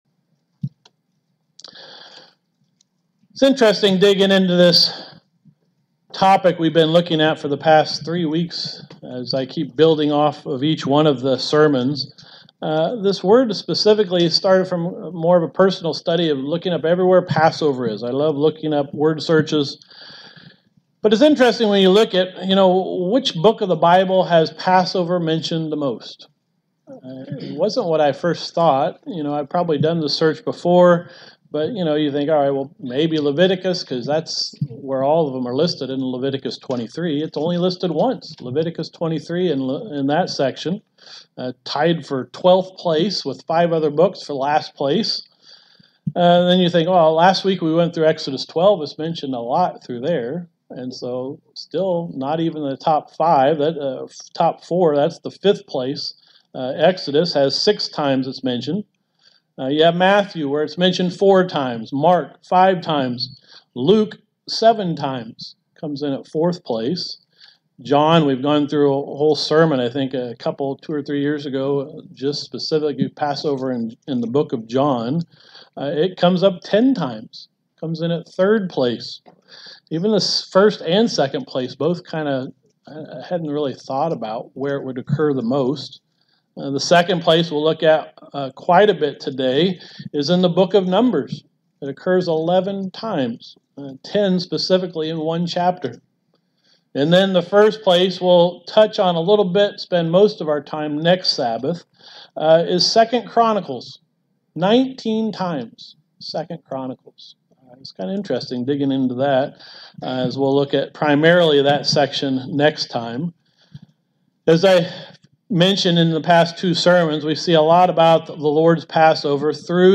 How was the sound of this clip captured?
Given in Elkhart, IN Northwest Indiana